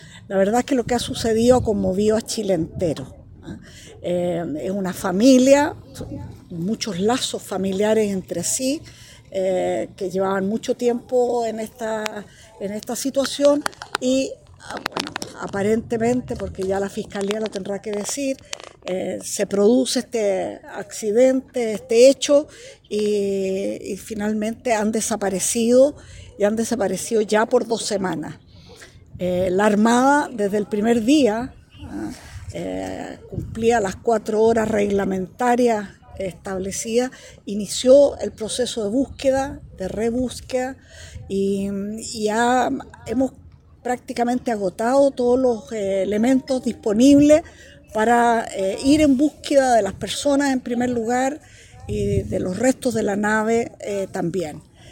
Tras la reunión con los familiares, la secretaria de Estado afirmó que “lo que ha sucedido ha conmovido a Chile entero” y añadió que “hemos venido a escuchar y acompañar a las familias, a traerles un abrazo de parte del Presidente de la República”.